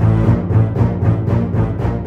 attention-danger.wav